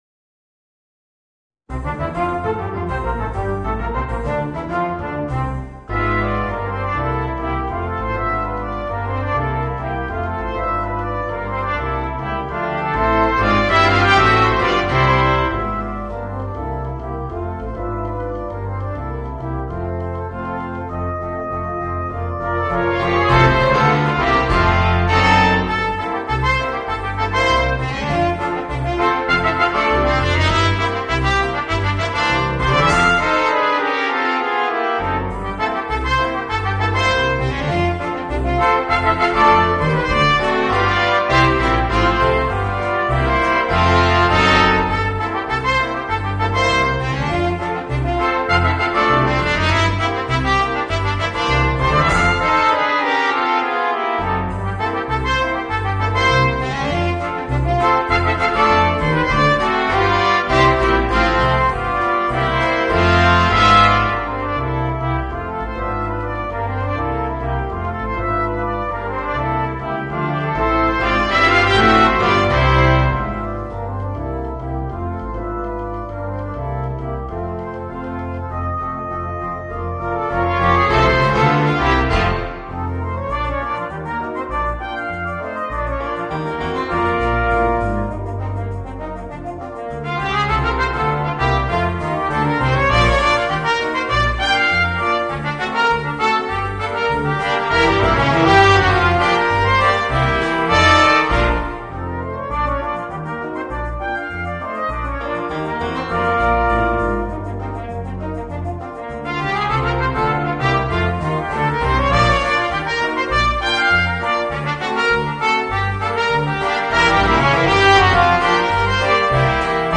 Voicing: 3 Trumpets, Horn and Trombone